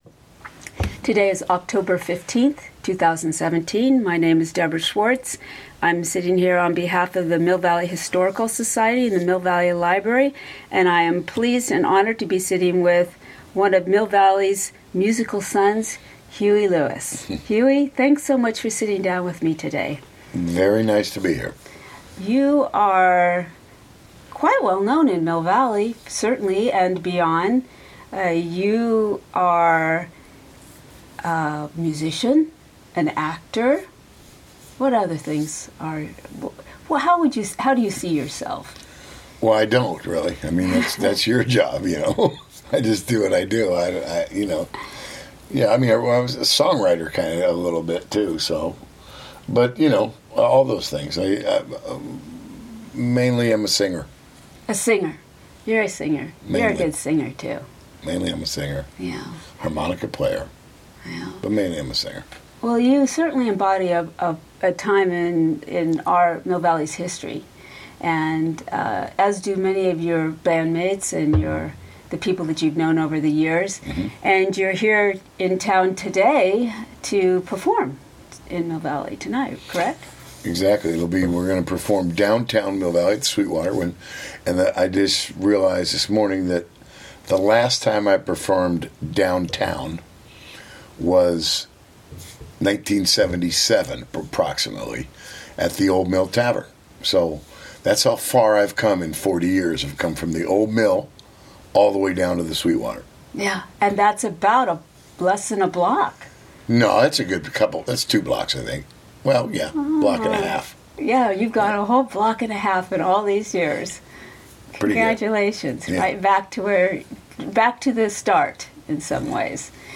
Oral History of Huey Lewis - Oral History | Mill Valley Public Library